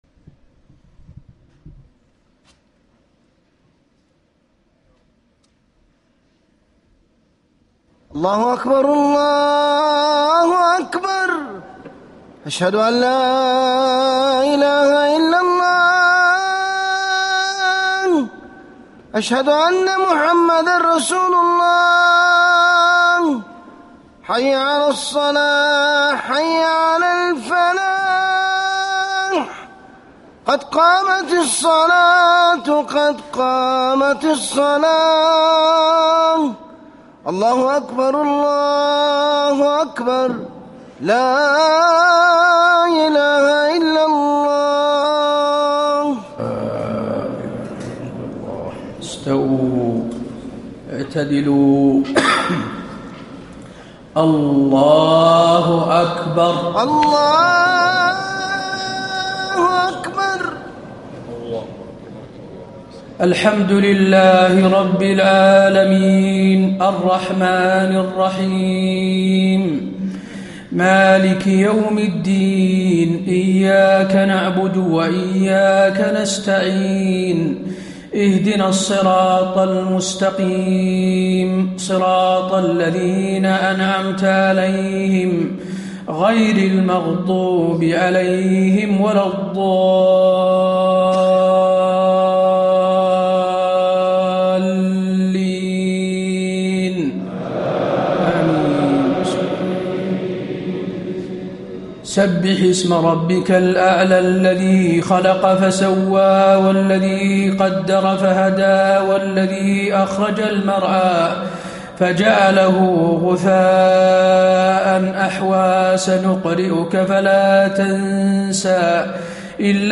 صلاة الجمعة 2-6-1434 سورتي الأعلى و الغاشية > 1434 🕌 > الفروض - تلاوات الحرمين